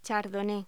Locución: Chardonnay